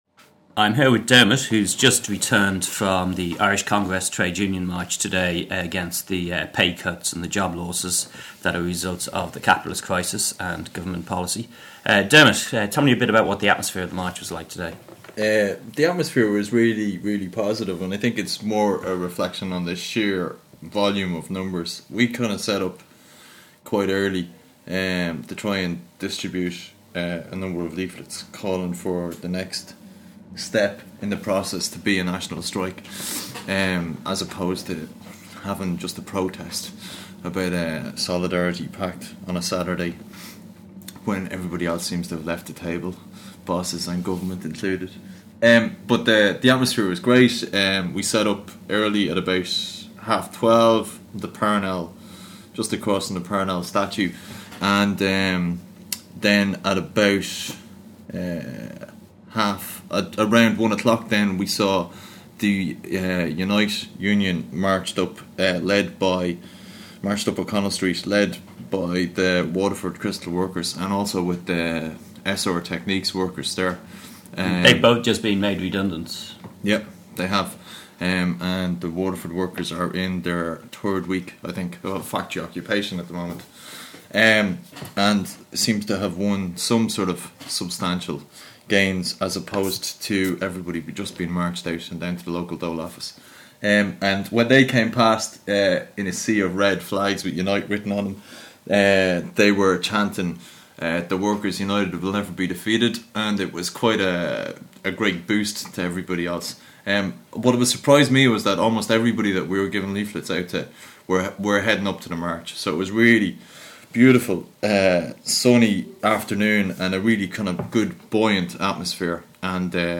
• Audio From The March Contributed By A WSM Member
Interview about the ICTU march against pay cuts and job losses
ictumarchinterview.mp3